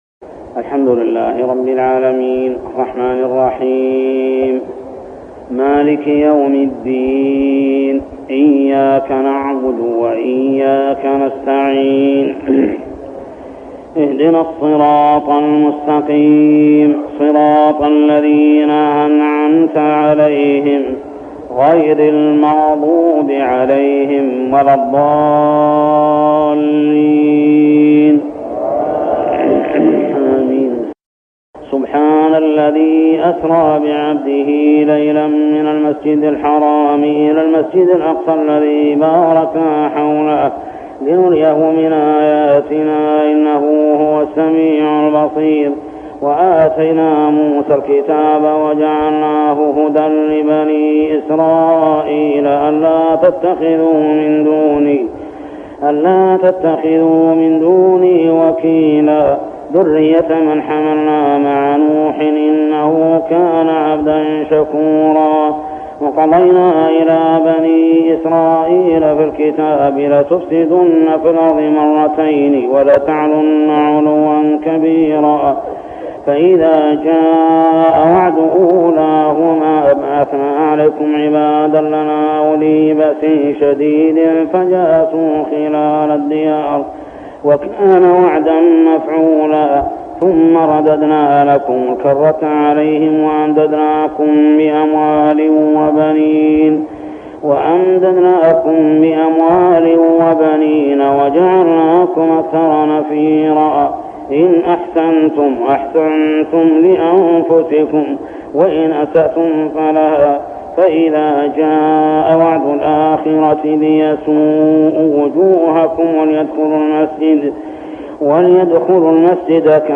من صلاة التراويح عام 1403هـ سورة الإسراء 1-96 | Tarawih prayer Surah Al-Isra > تراويح الحرم المكي عام 1403 🕋 > التراويح - تلاوات الحرمين